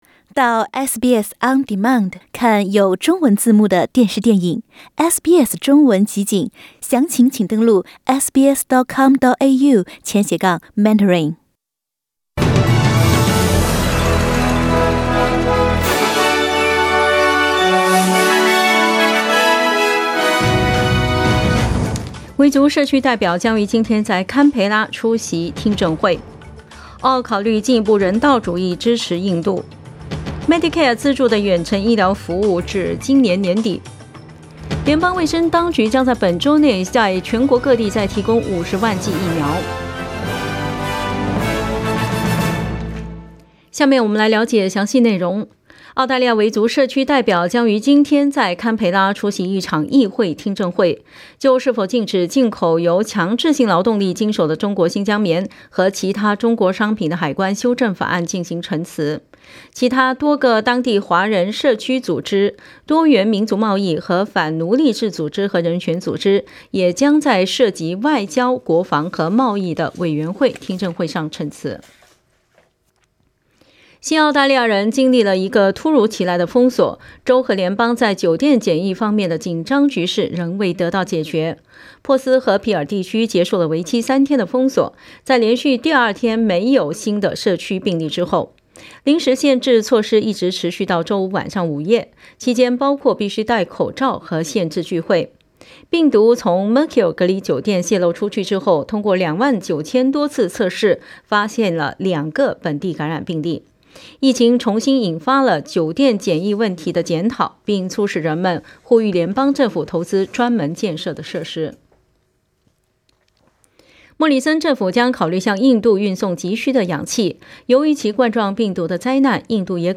SBS早新聞 （4月27日）
SBS Mandarin morning news Source: Getty Images